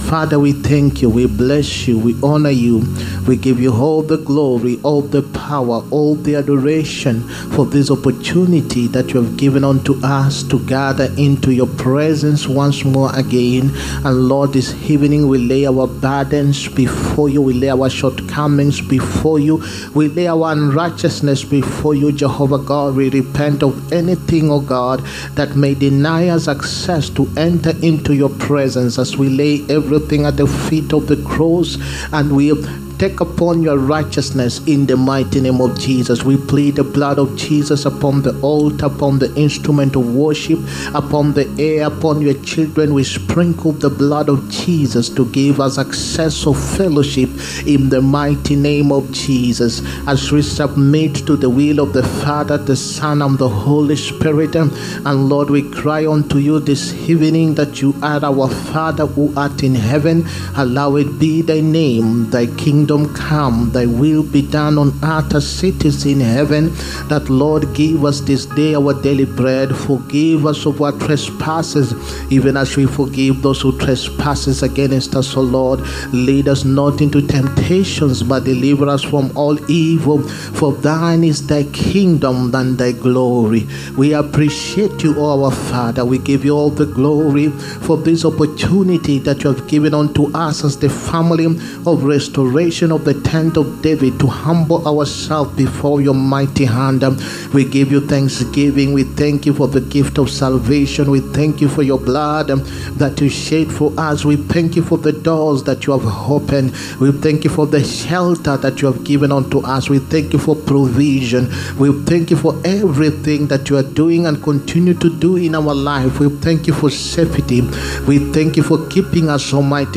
HEALING, PROPHETIC AND DELIVERANCE SERVICE. 8TH MARCH 2025.